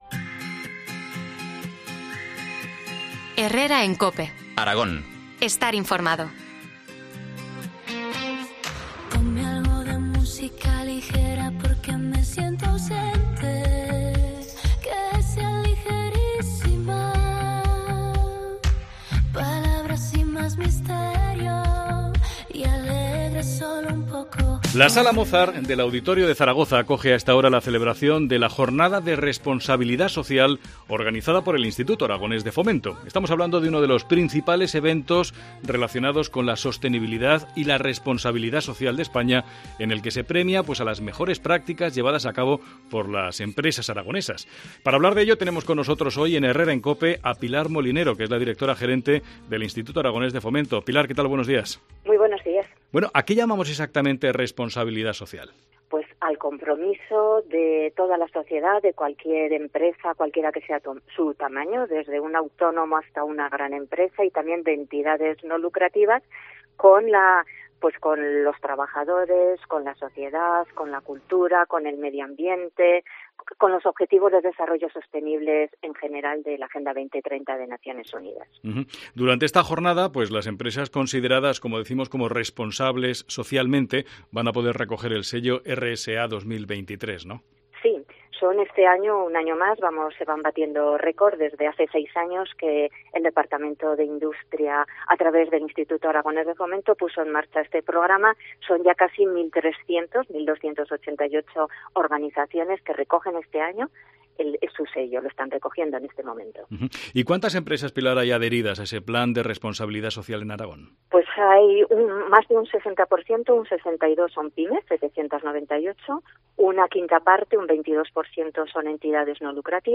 Entrevista a Pilar Molinero, directora del Instituto Aragonés de Fomento.